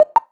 select.wav